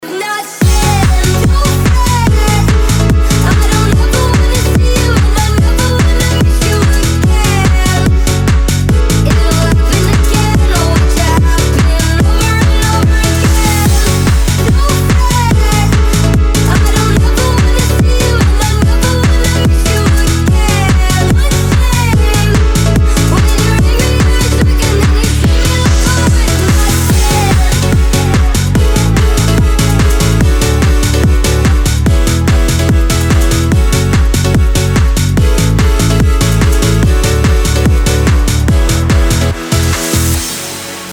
из Клубные
Категория - клубные.